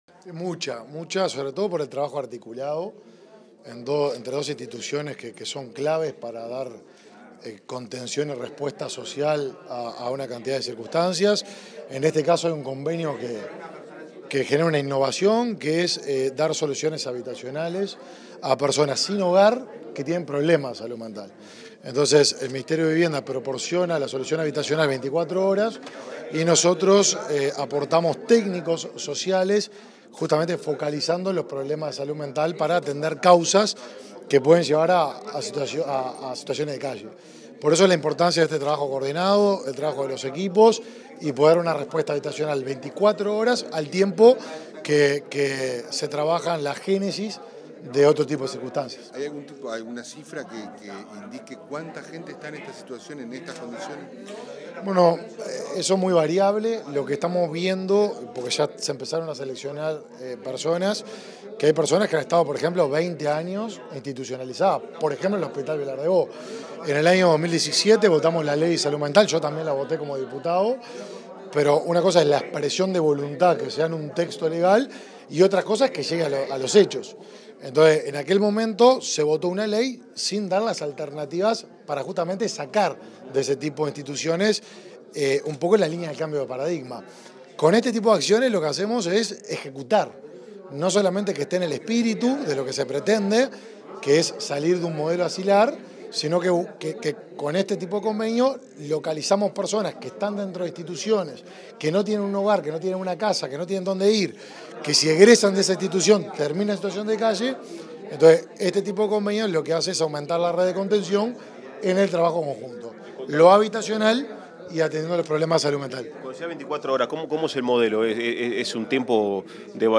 Declaraciones del ministro de Desarrollo Social, Martín Lema 02/10/2023 Compartir Facebook X Copiar enlace WhatsApp LinkedIn Luego de firmar dos convenios con el Ministerio de Vivienda y Ordenamiento Territorial, este 2 de octubre, el ministro de Desarrollo Social, Martín Lema, realizó declaraciones a la prensa.